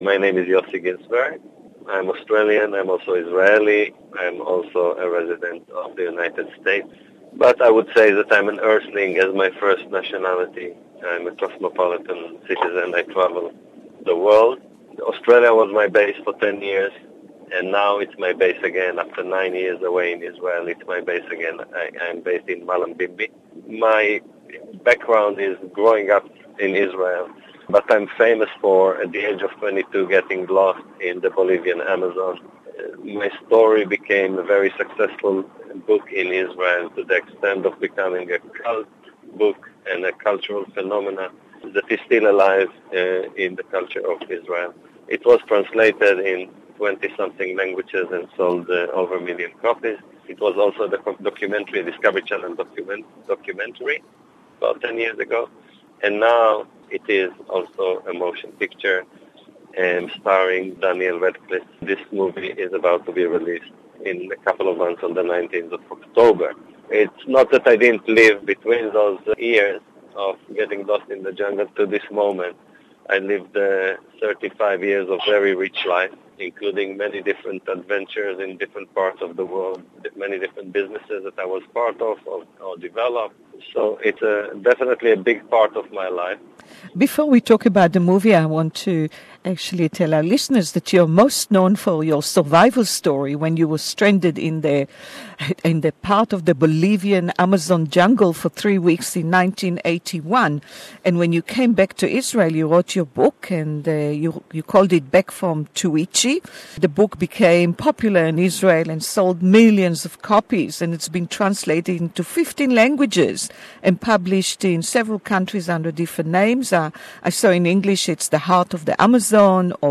Yossi Ghinsberg "Jungle" Excited to see Daniel Radcliffe, acting his story on the big screen...English Interview